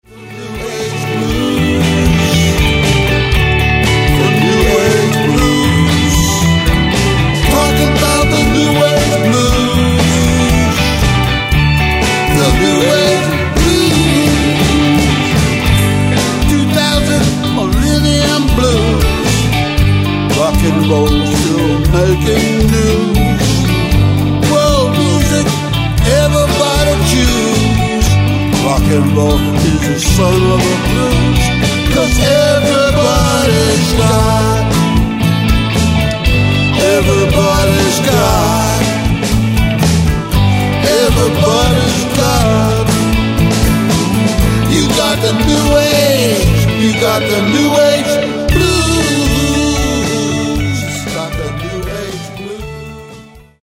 Genre: Rock & Roll.